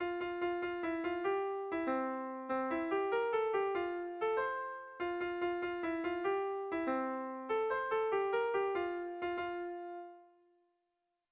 Irrizkoa
Ataun < Goierri < Gipuzkoa < Euskal Herria
Lau puntuko berdina, 8 silabaz
ABAD